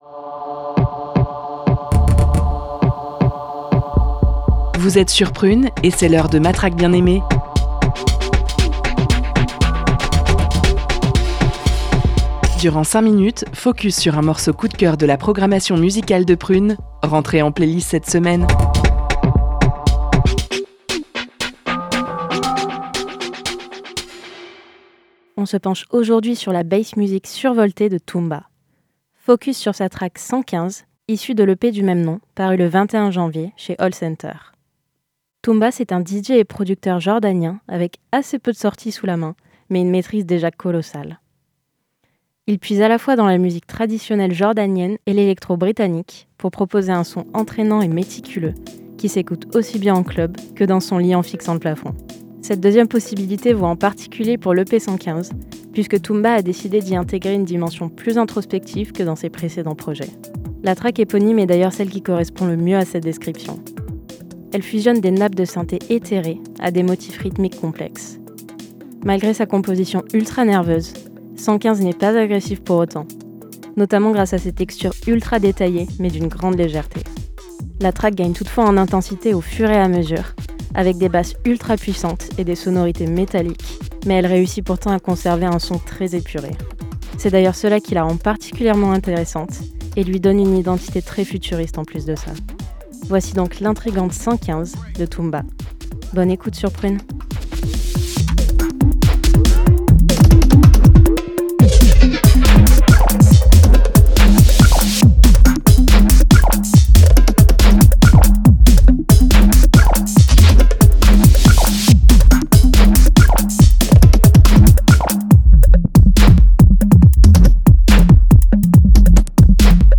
bass musique survoltée